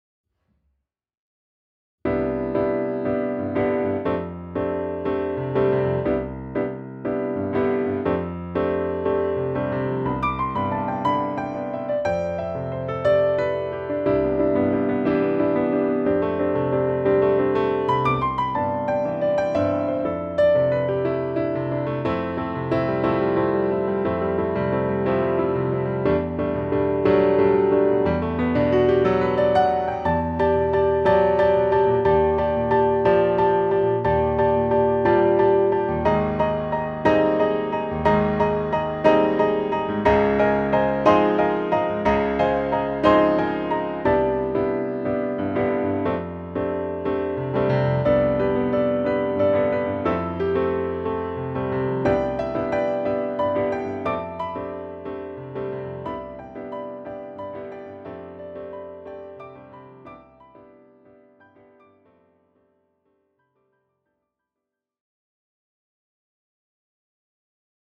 I played a short improv on the piano before she lost interest.